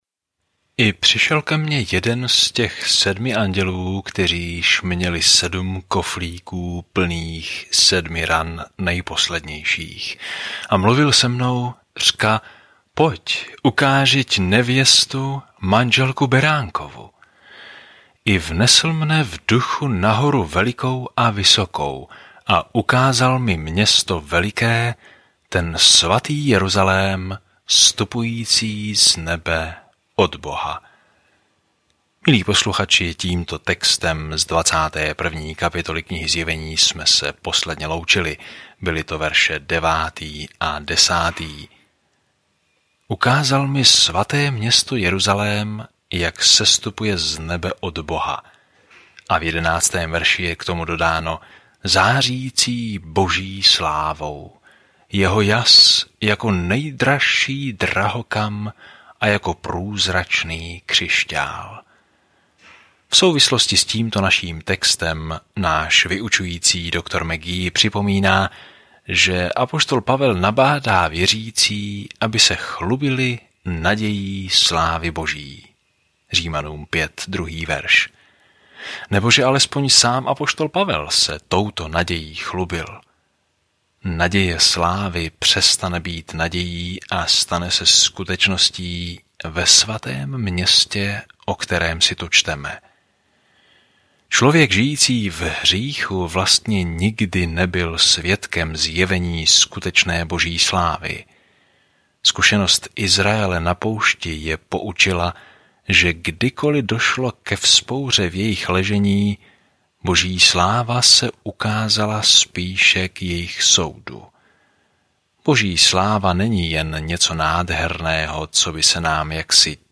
Písmo Zjevení 21:10-20 Den 63 Začít tento plán Den 65 O tomto plánu Zjevení zaznamenává konec rozsáhlé časové osy dějin s obrazem toho, jak bude se zlem konečně zacházeno a Pán Ježíš Kristus bude vládnout ve vší autoritě, moci, kráse a slávě. Denně procházejte Zjevení a poslouchejte audiostudii a čtěte vybrané verše z Božího slova.